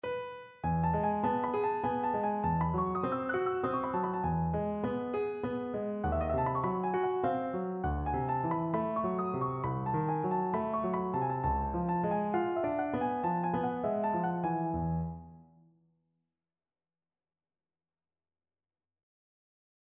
This project included the task for me to write three different ornamented versions of the following melody written for the piano: